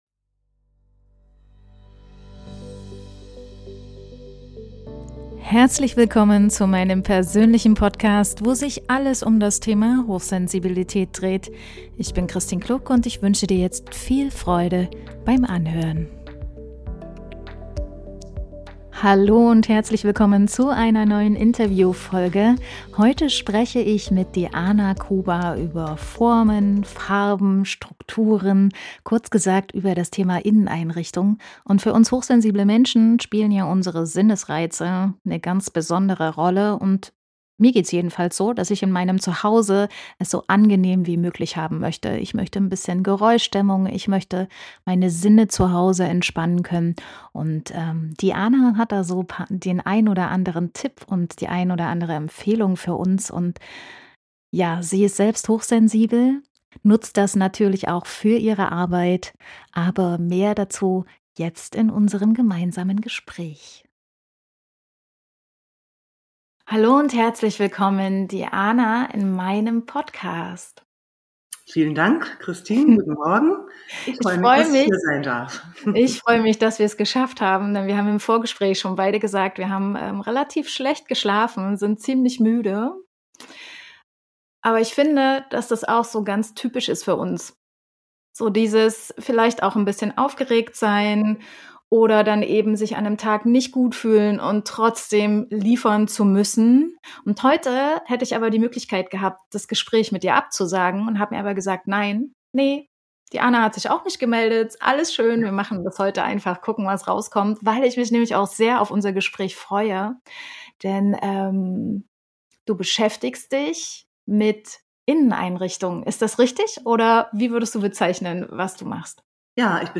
Bei diesem Video handelt es sich um einen Zusammenschnitt unserer Aufnahme, in welchem wir die wichtigsten Punkte thematisieren.